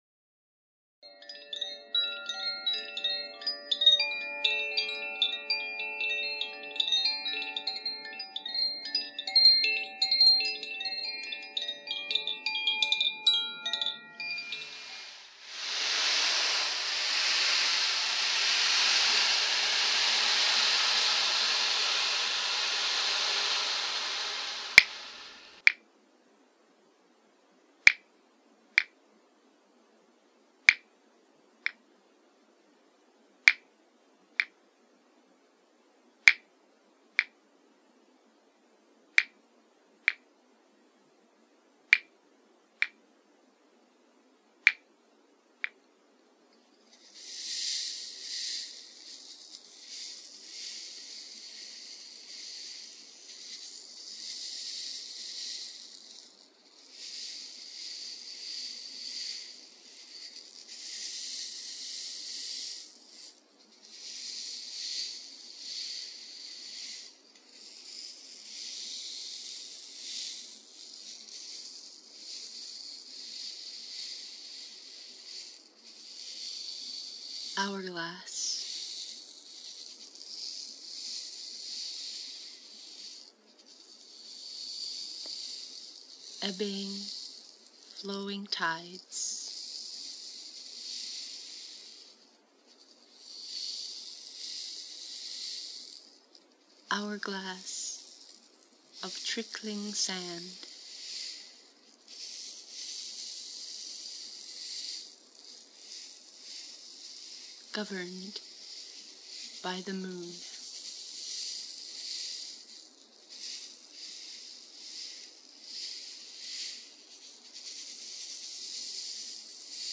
Flute: Nova Double Flute in A Minor + Koshi Aqua Chime, Ocean Drum, Redwood Claves, Hourglass Shaker, Zaphir Sufi Chime
14860-hourglass-flutehaiku.mp3